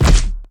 z_hit_5.ogg